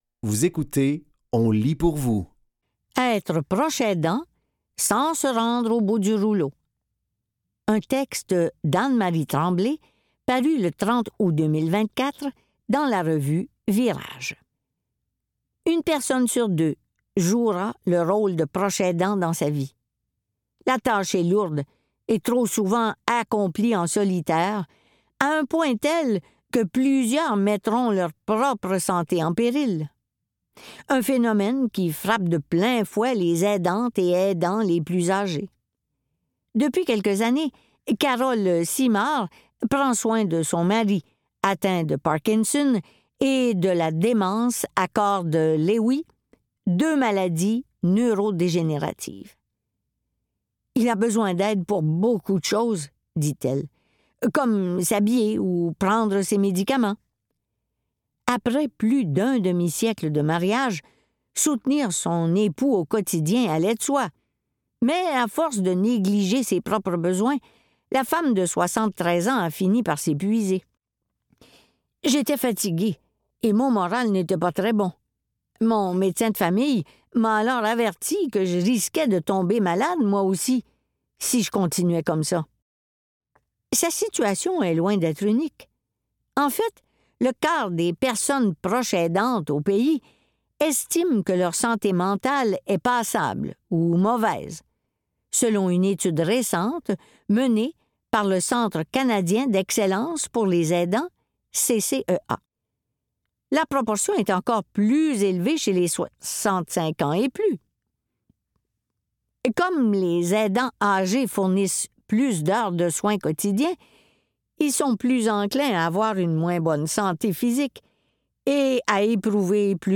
Dans cet épisode de On lit pour vous, nous vous offrons une sélection de textes tirés des médias suivants : Virage, ICI Toronto et La Presse.